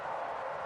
slide.wav